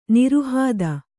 ♪ niruhāda